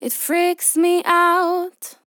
Vocal Sample DISCO VIBES
dry
female
Categories: Vocals